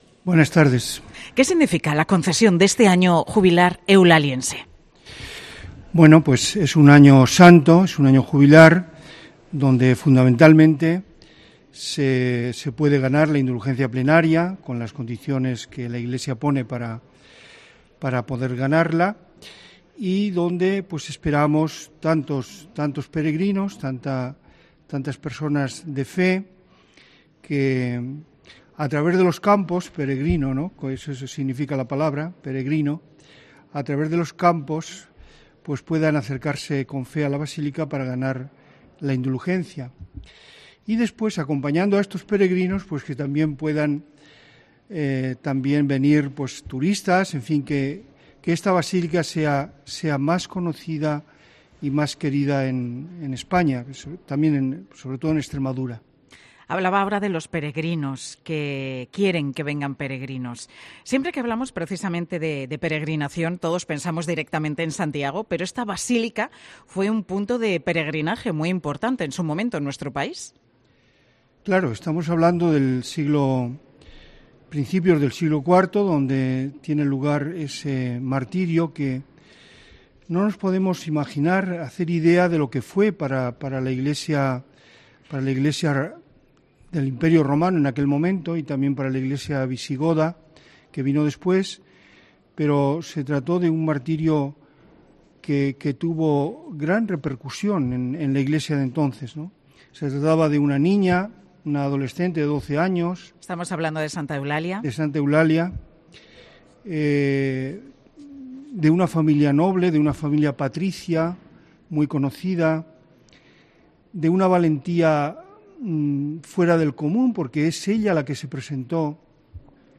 Mediodía COPE se ha trasladado hasta la basílica de Santa Eulalia, donde el 10 de diciembre de 2023 arrancará el Año Santo en honor a la mártir y patrona de la capital extremeña
En la víspera de que arranque este Año Jubilar, 'Mediodía COPE' ha realizado el programa en la Basílica de Santa Eulalia.